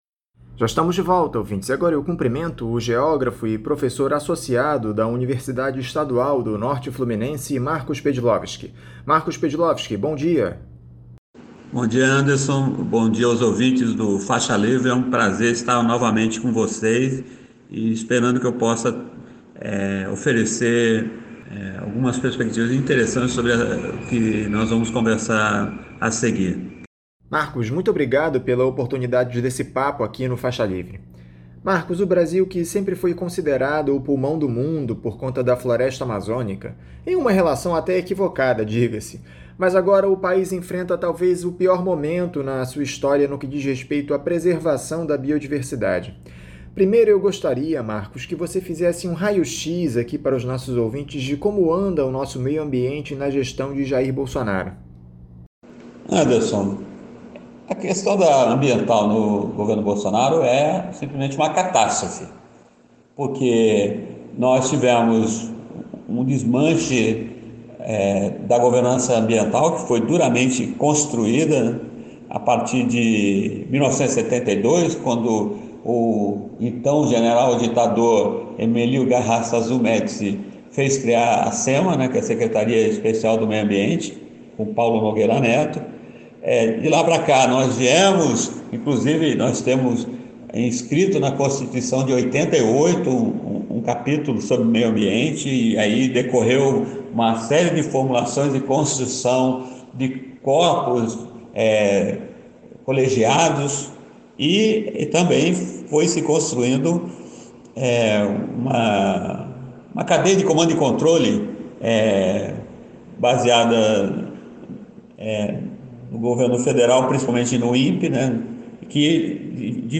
Entrevista ao Programa Faixa Livre: a questão ambiental no governo Bolsonaro